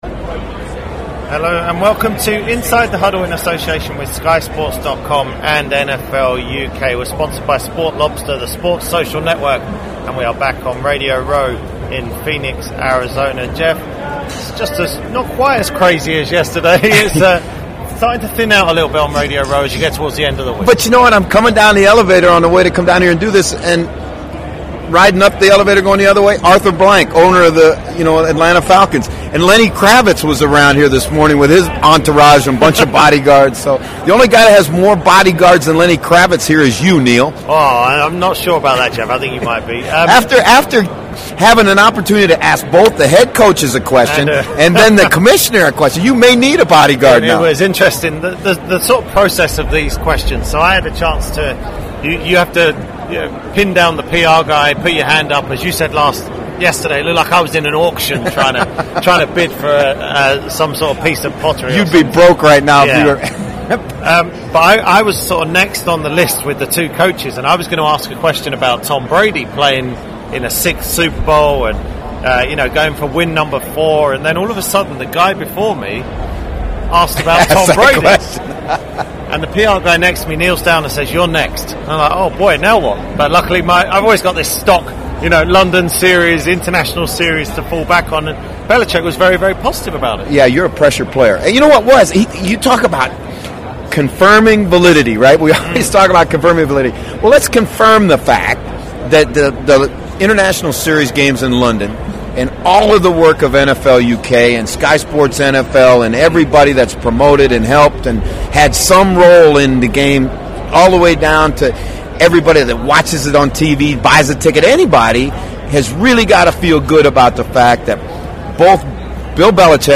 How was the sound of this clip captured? check in from Radio Row in Phoenix and respond to a big day of press conferences in Arizona.